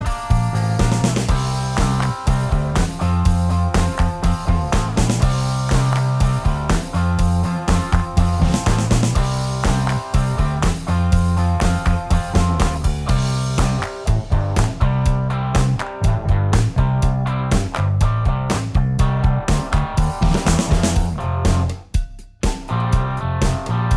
Minus Lead Guitar Rock 3:34 Buy £1.50